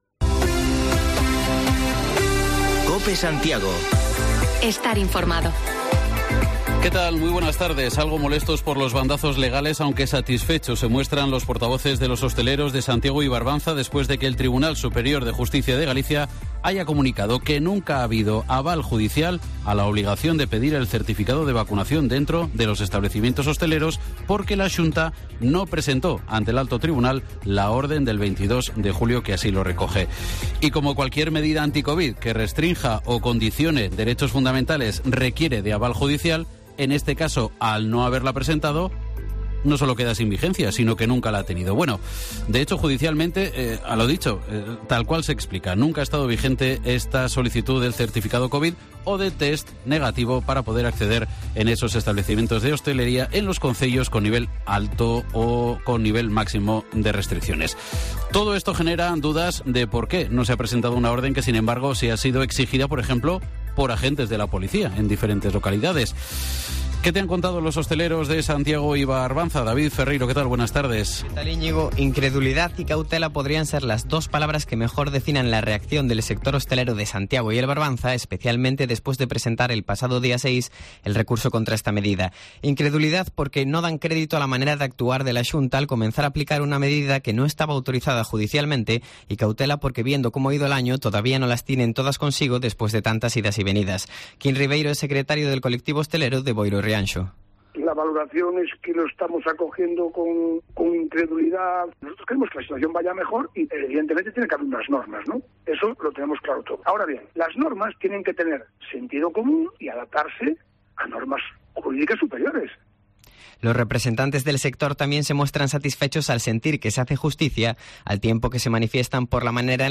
Informativo local Mediodía en Cope Santiago y de las Rías 12/08/2021